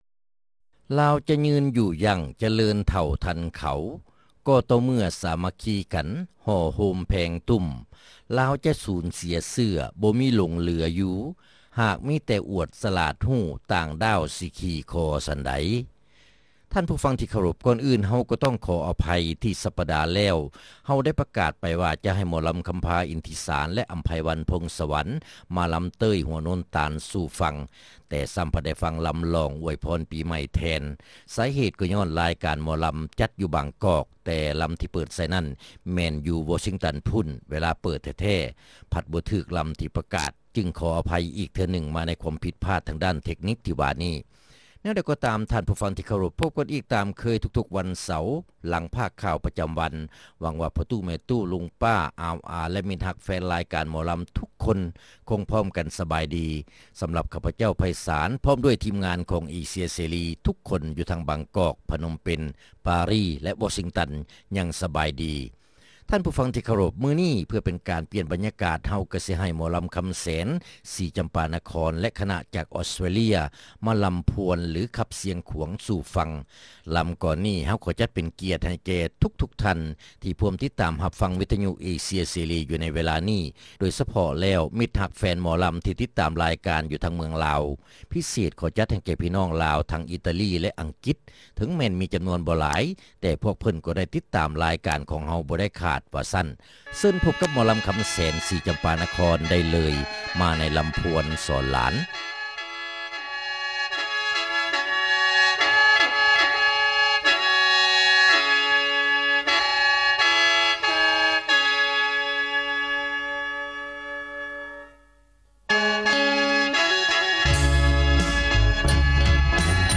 ຣາຍການໜໍລຳ ປະຈຳສັປະດາ ວັນທີ 19 ເດືອນ ມົກກະຣາ ປີ 2007